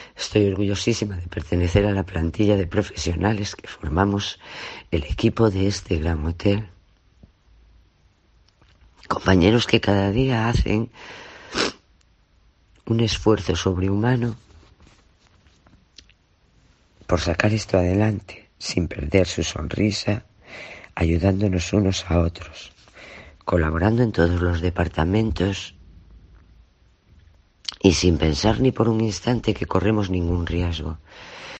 El emocionado testimonio de una empleada del hotel de Adeje: "Seguiremos hasta que todo esto acabe"